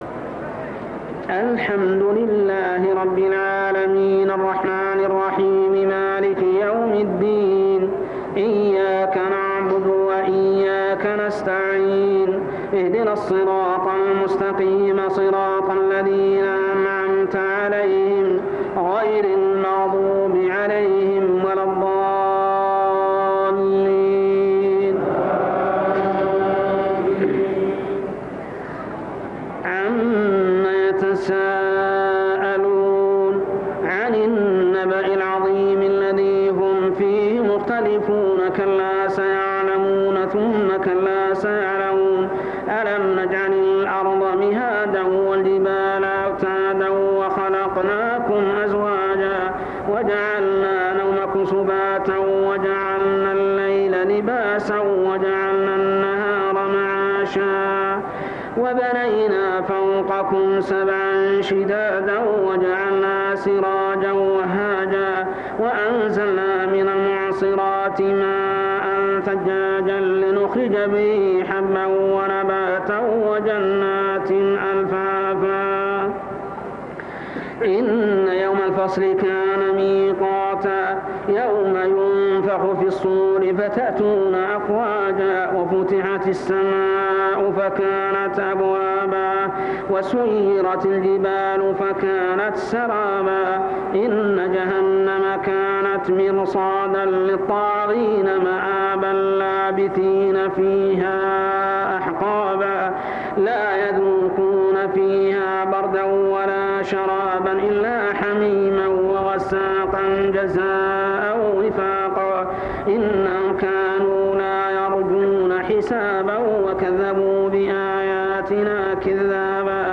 صلاة التراويح عام 1397هـ من سورة النبإ كاملة حتى سورة الغاشية كاملة | Tarawih prayer from Surah Al-Naba to surah Al-Ghashiyah > تراويح الحرم المكي عام 1397 🕋 > التراويح - تلاوات الحرمين